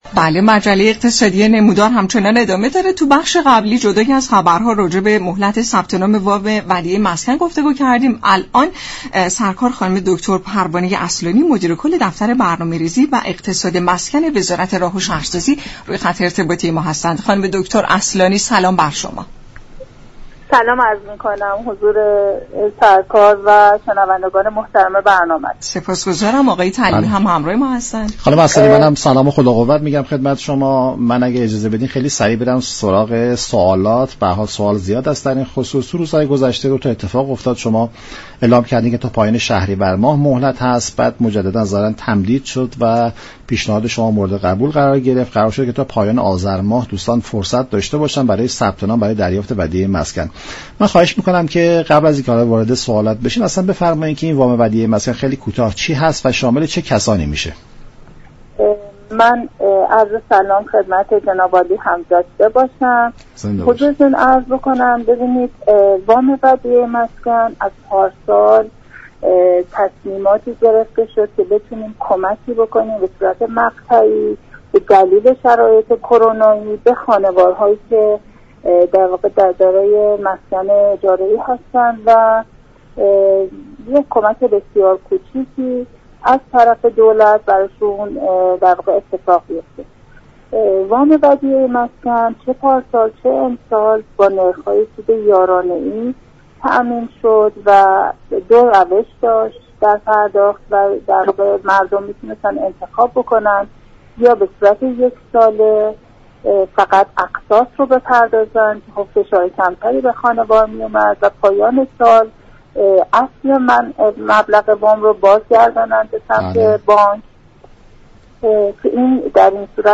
به گزارش شبكه رادیویی ایران، پروانه اصلانی مدیر كل دفتر برنامه ریزی و اقتصاد مسكن وزارت راه و شهرسازی در برنامه «نمودار» درباره جزئیات وام ودیعه مسكن پرداخت و گفت: در شرایط امروز كرونا وام دیعه مسكن كمك كوچك دولت به مستاجران است.